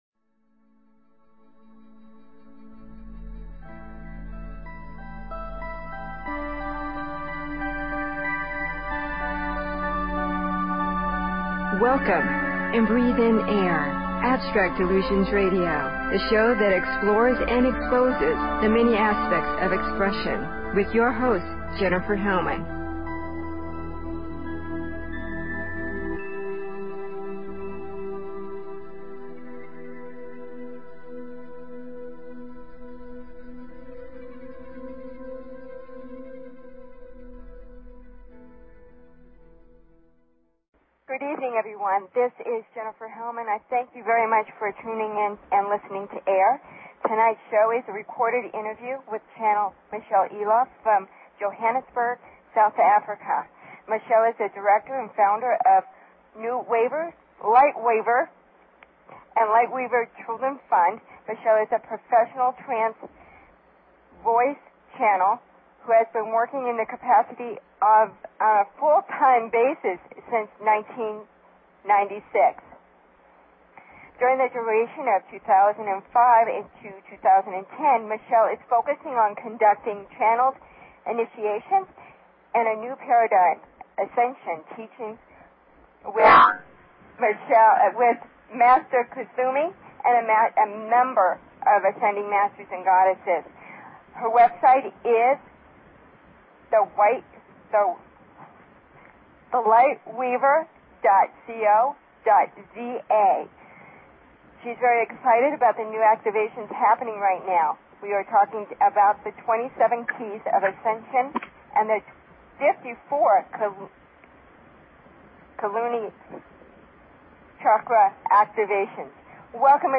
Talk Show Episode, Audio Podcast, Abstract_Illusion_Radio and Courtesy of BBS Radio on , show guests , about , categorized as
Mercury Retrograde was very active and we had many technical problems during the pre recorded call.